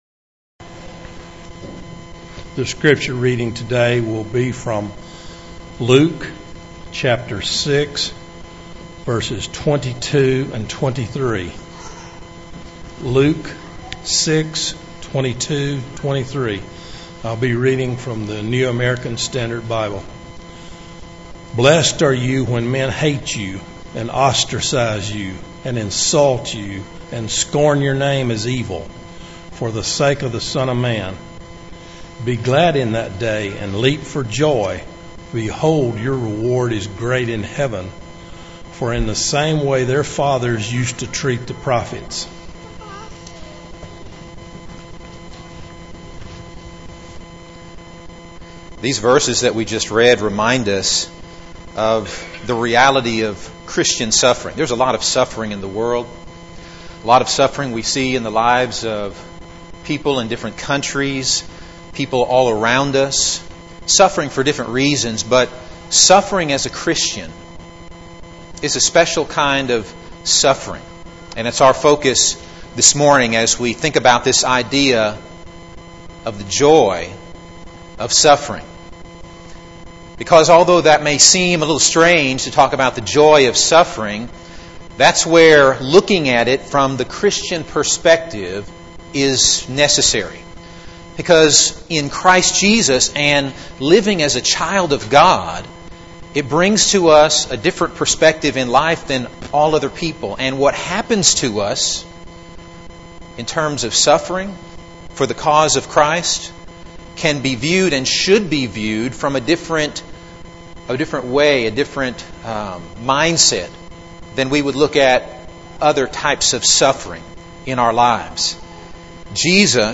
Eastside Sermons Service Type: Sunday Morning « Wednesday Evening Youth Service